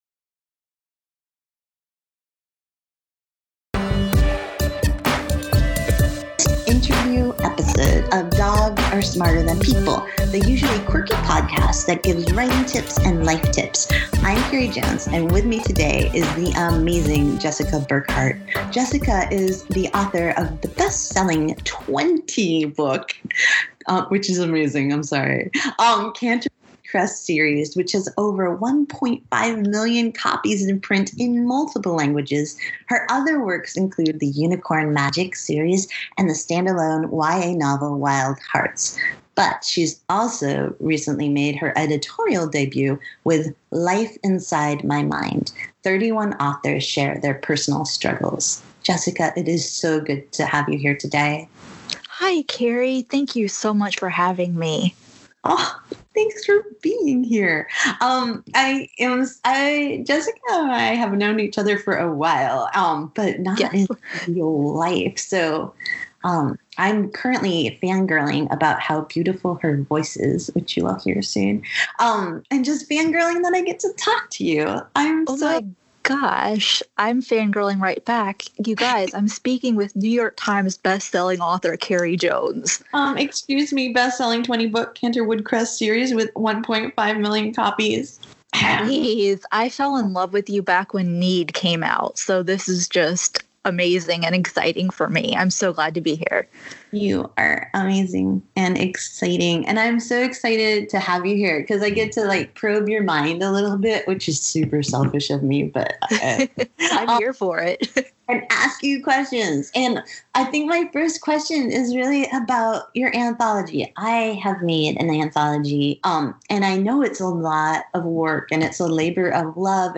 Welcome to a bonus interview episode of Dogs are Smarter Than People, the usually quirky podcast that gives writing tips and life tips.